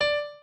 pianoadrib1_24.ogg